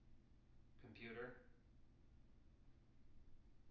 wake-word
tng-computer-347.wav